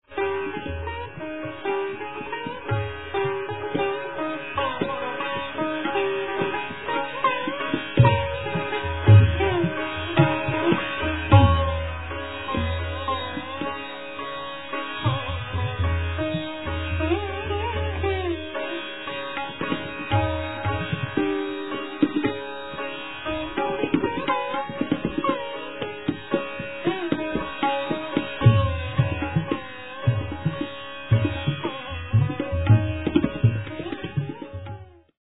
sitar and surbahar (bass sitar)